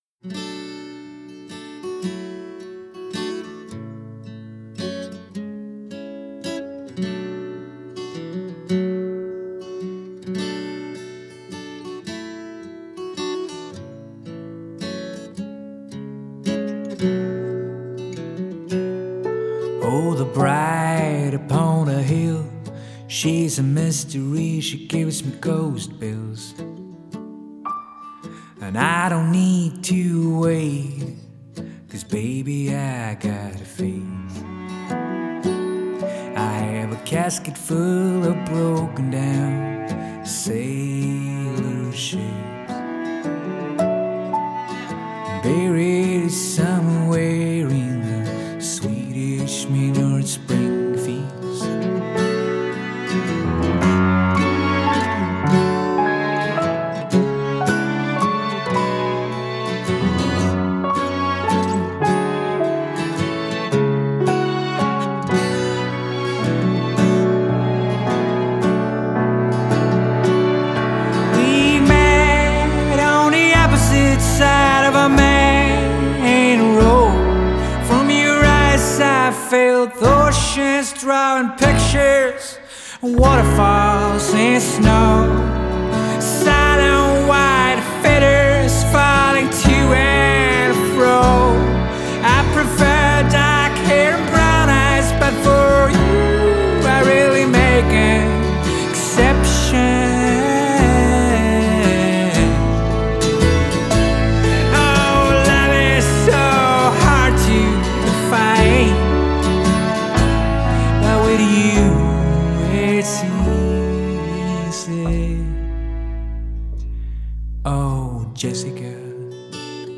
so here's a great uneasy ballad from said disc.